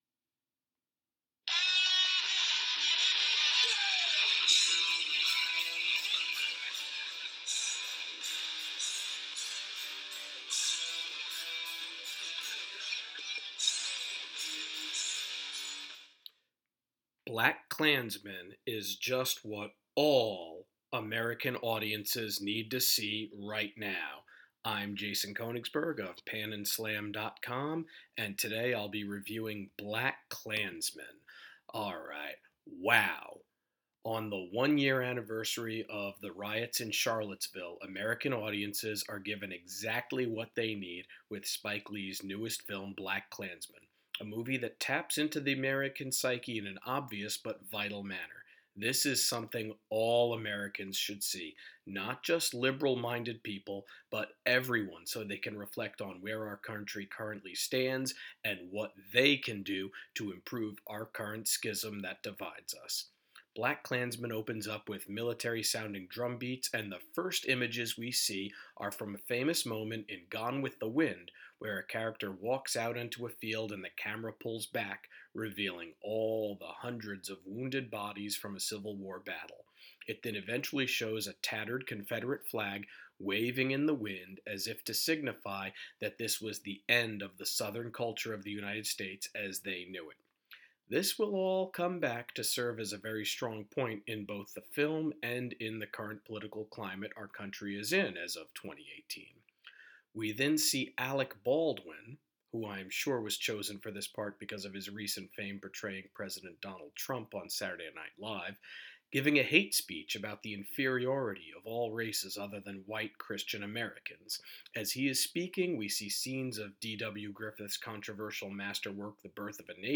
Movie Review: BlackkKlansman